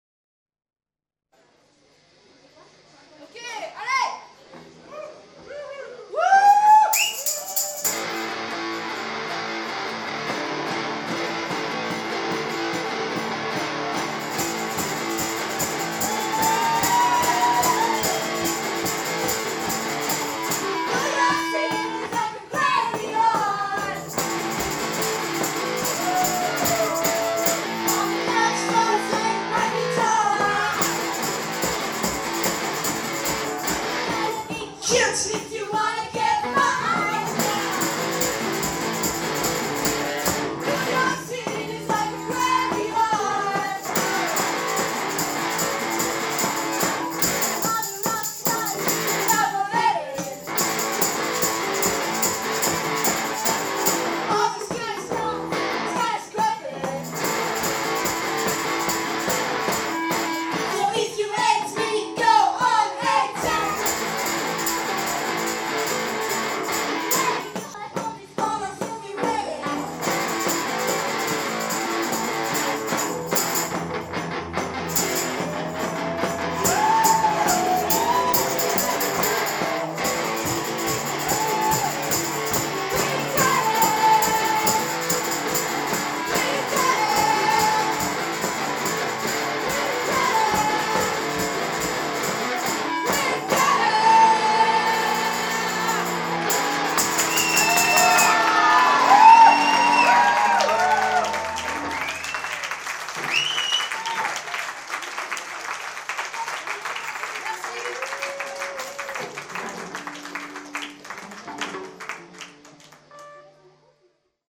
Live à la Rockschool de Barbey